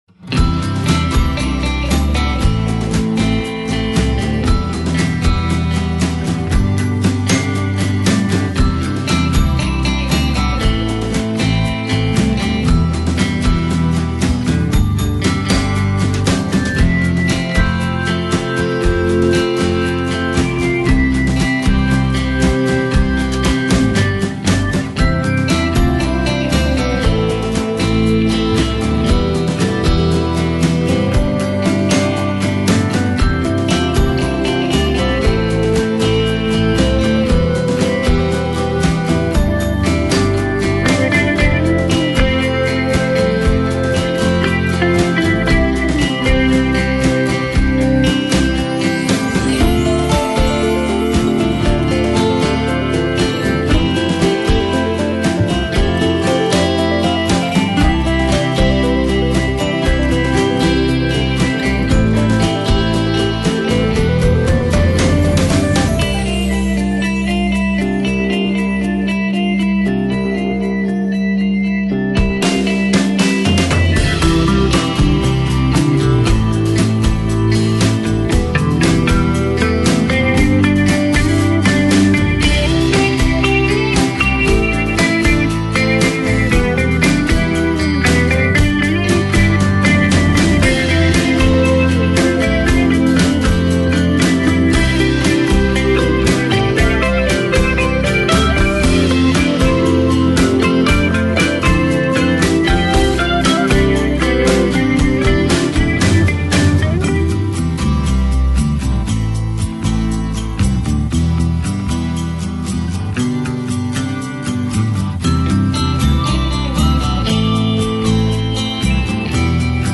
Warm, Kraftvoll, Undeutlich.
Erzähler und Stauspieler.